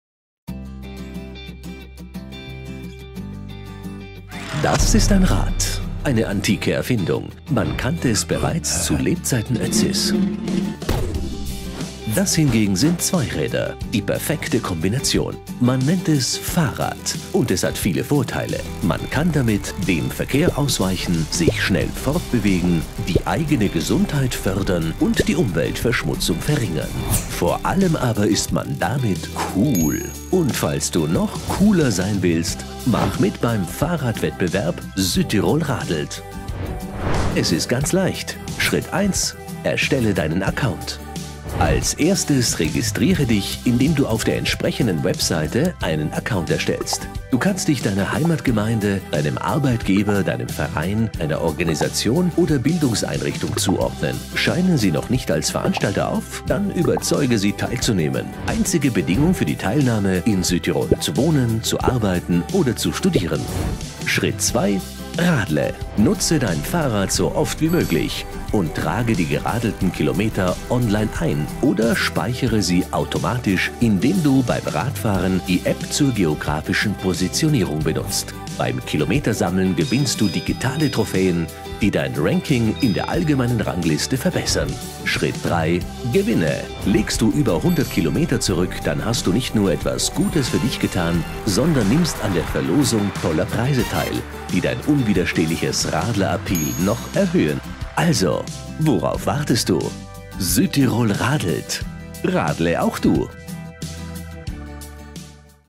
Professioneller Sprecher mit markanter, warmer, sympathischer Stimme im besten Alter + Homestudio
Sprechprobe: eLearning (Muttersprache):